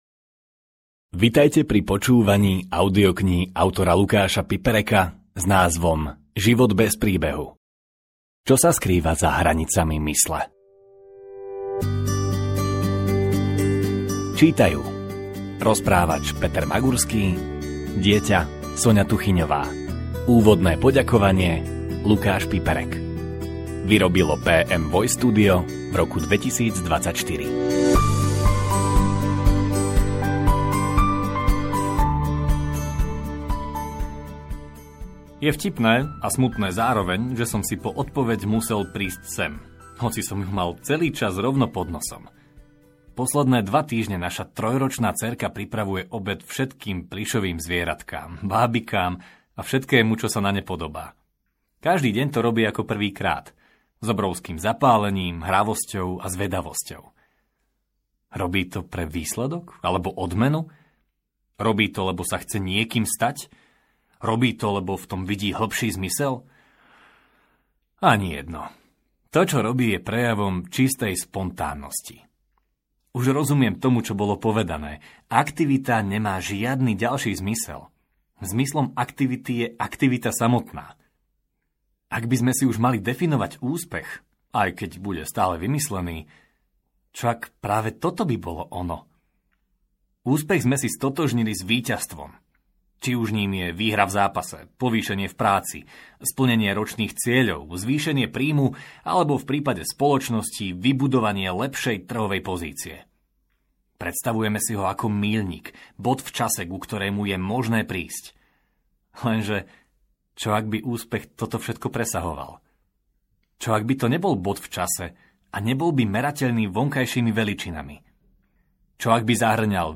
Život bez príbehu audiokniha
Ukázka z knihy